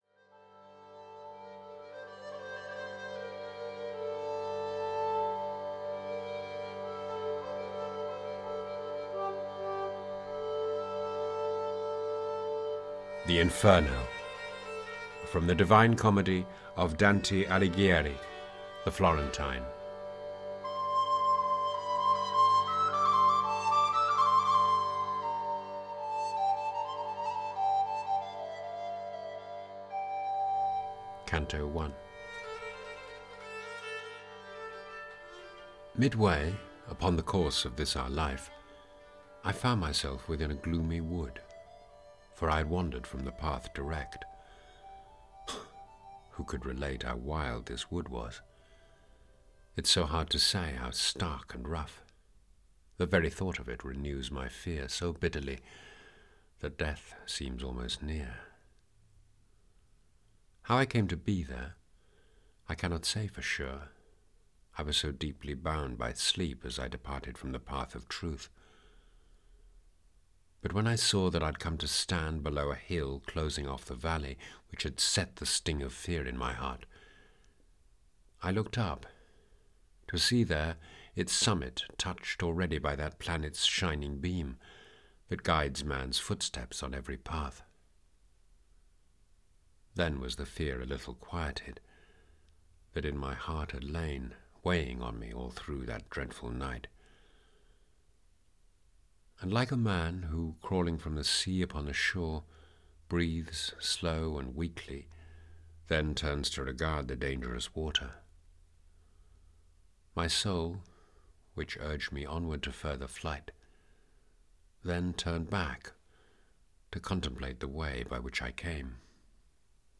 The Inferno (EN) audiokniha
Ukázka z knihy
• InterpretHeathcote Williams